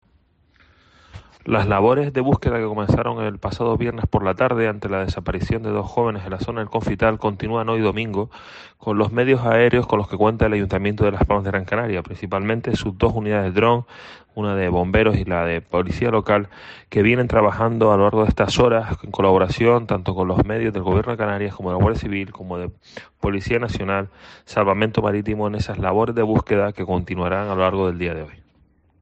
Josué Iñiguez, concejal de Seguridad del ayuntamiento de Las Palmas de Gran Canaria